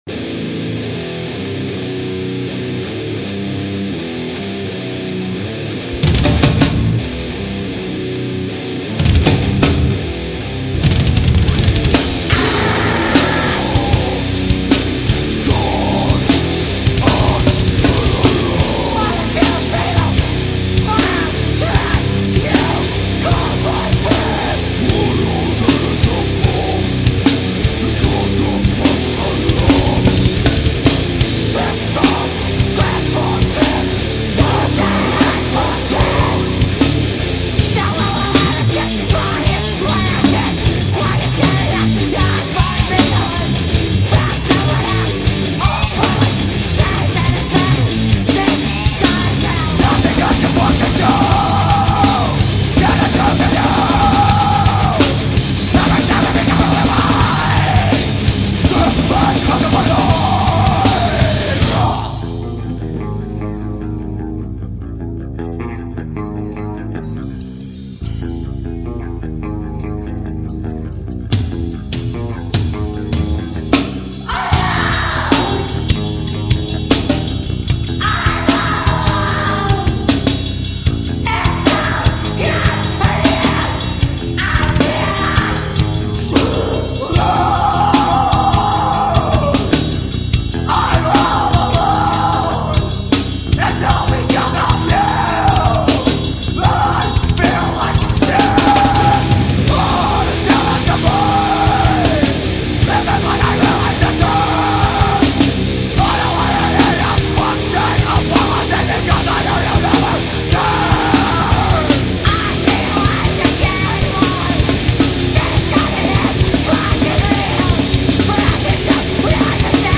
Arizona 3 piece
This record never lets up for a second!